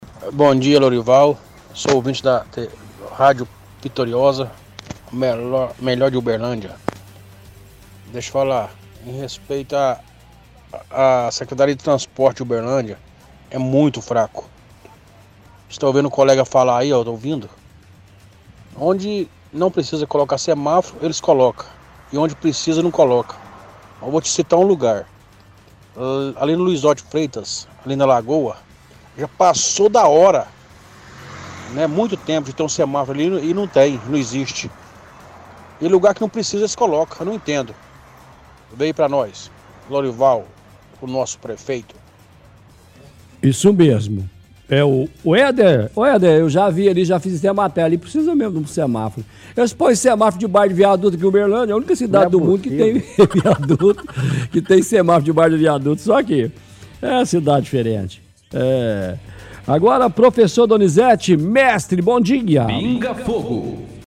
– Ouvinte reclama da SETTRAN reforça que não são eficientes onde instalam semáforo, fala da Luizote de Freitas onde é necessário.